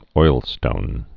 (oilstōn)